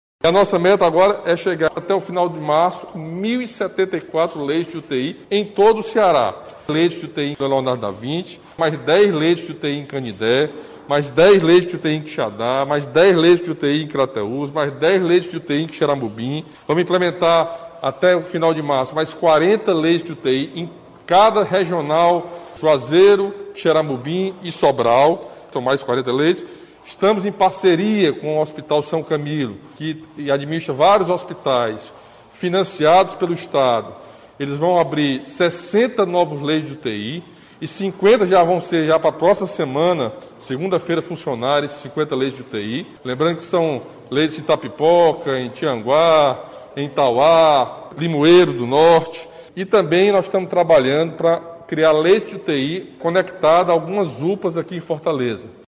Em visita de vistoria às obras das novas UTIs do Hospital Leonardo Da Vinci, o governador Camilo Santana fez transmissão ao vivo e anunciou que até o fim de fevereiro, o Ceará vai ter 811 leitos de UTI exclusivos para Covid-19, 108 a mais que o previsto.